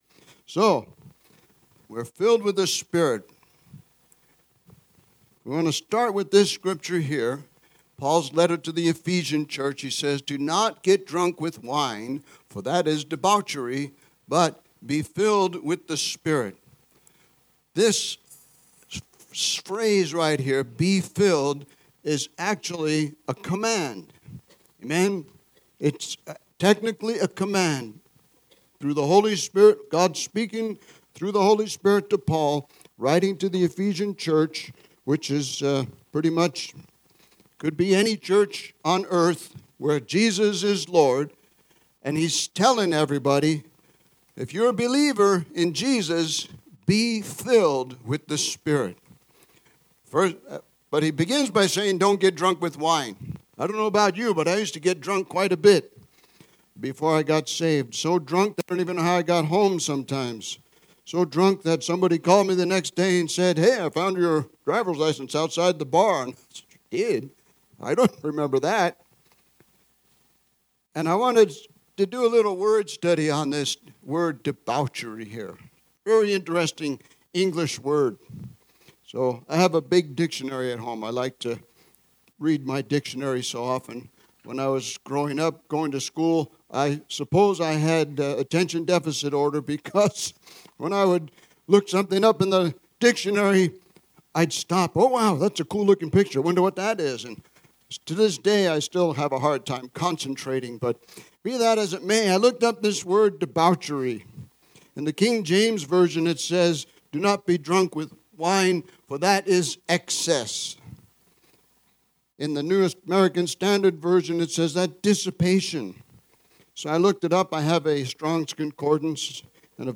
Living Hope Family Church is a spirit-filled, evangelical, non-denominational Christian church in the Marana/Northwest Tucson area.